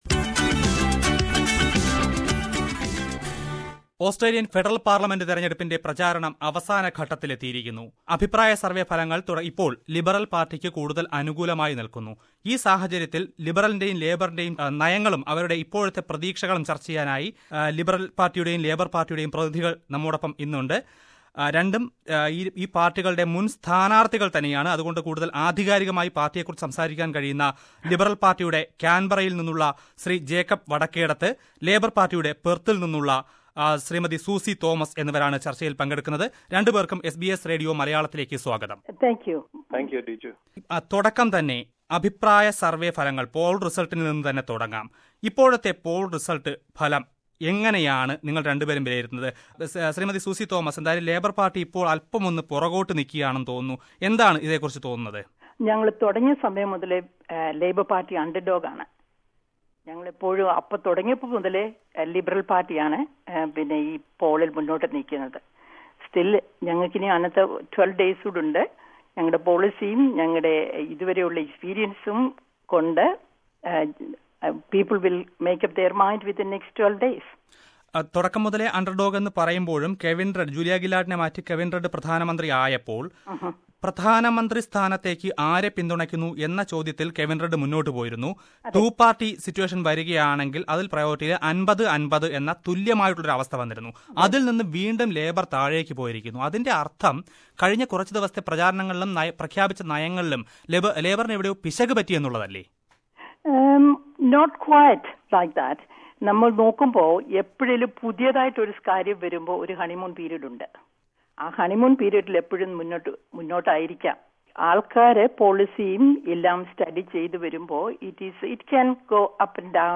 SBS Malayalam brings to you a heated debate between the Malayalee leaders of the major political parties in Australia. Here they discuss the major policy initiatives and general situation of the country....